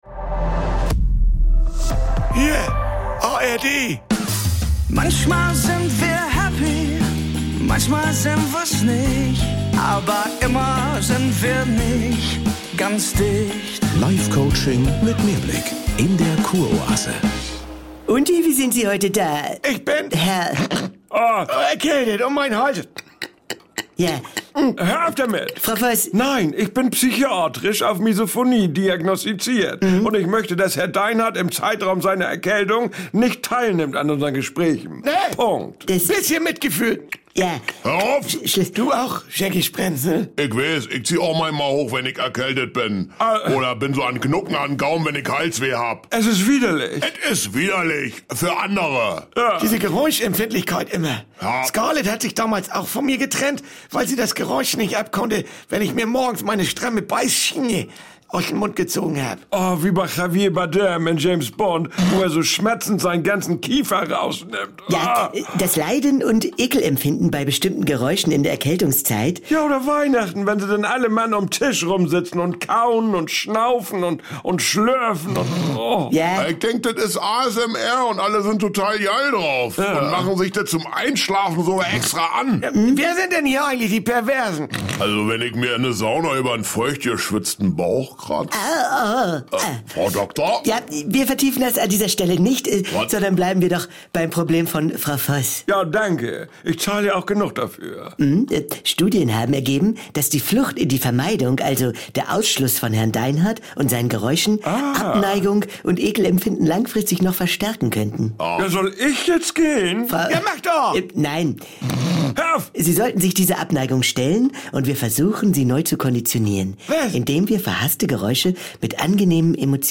Triggerwarnung: Es folgen gleich widerliche Geräusche!